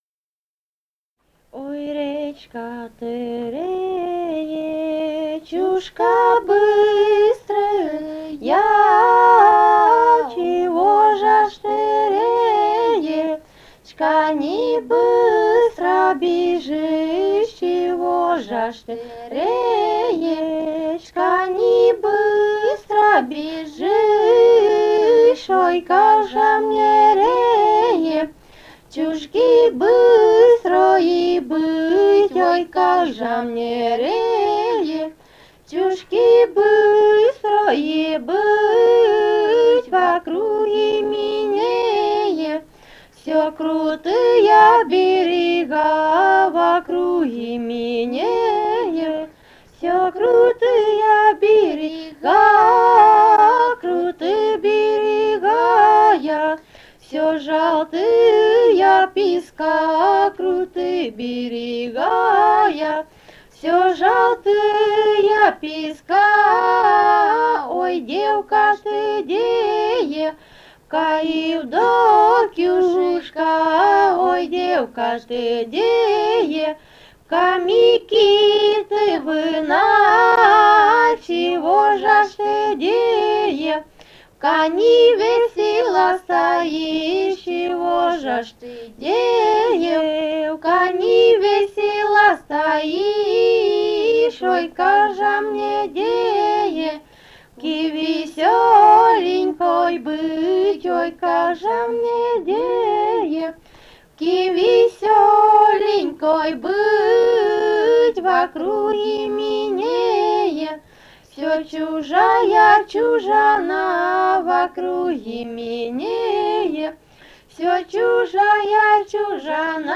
Этномузыкологические исследования и полевые материалы
«Ой, речка ты, речушка» (свадебная).
Румыния, с. Переправа, 1967 г. И0974-10